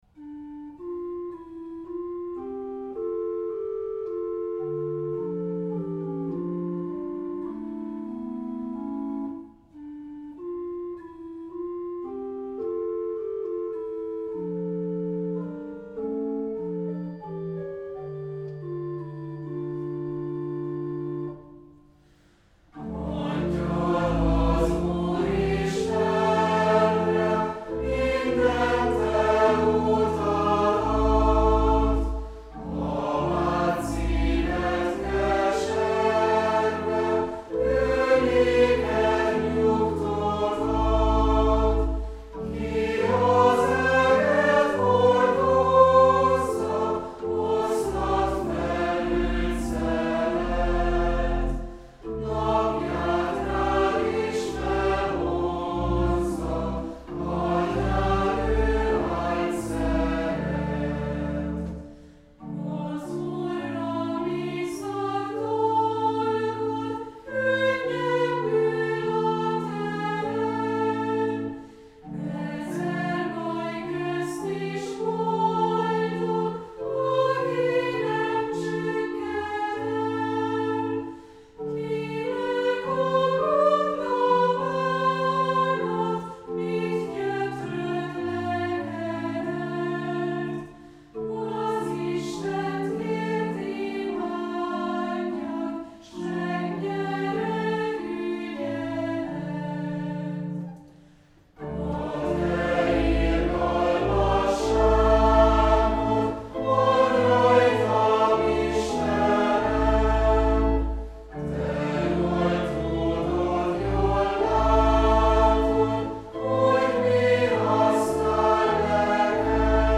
Az ének kottája (PDF) Az ének kottája (PNG) Hangfelvétel Korálkíséret